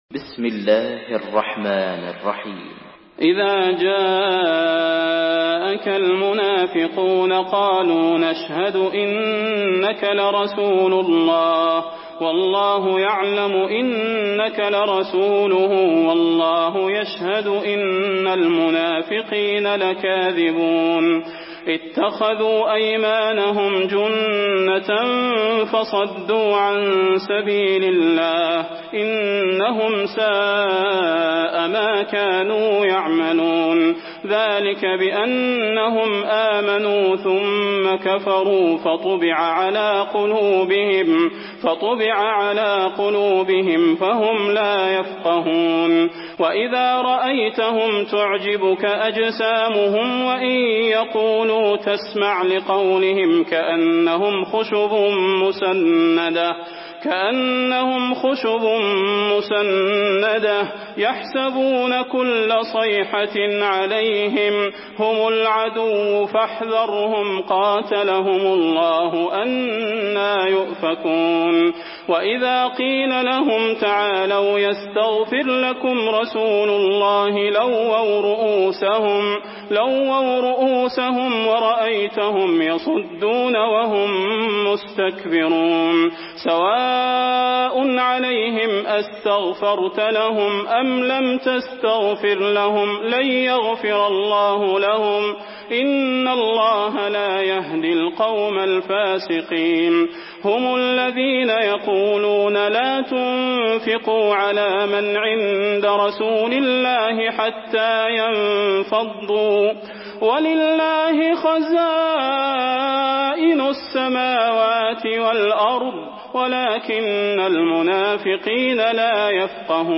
سورة المنافقون MP3 بصوت صلاح البدير برواية حفص عن عاصم، استمع وحمّل التلاوة كاملة بصيغة MP3 عبر روابط مباشرة وسريعة على الجوال، مع إمكانية التحميل بجودات متعددة.
مرتل